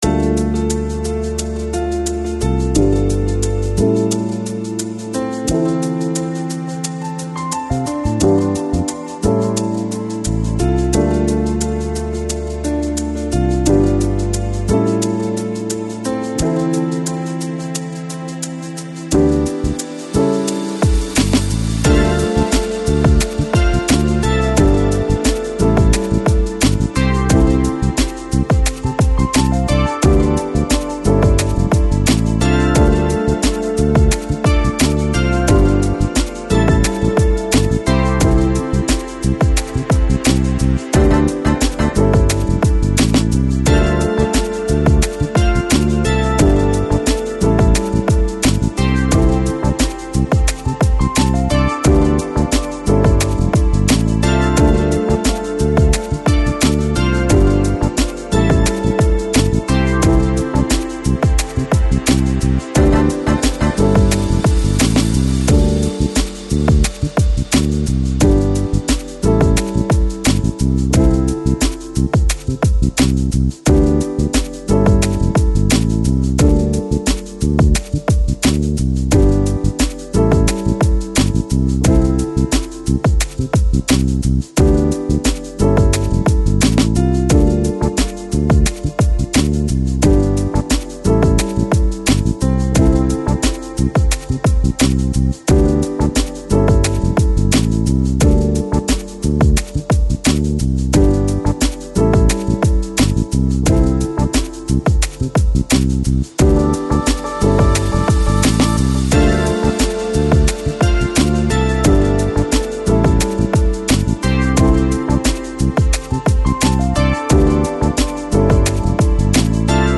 Жанр: Electronic, Lounge, Lo Fi, Downtempo, Chill Out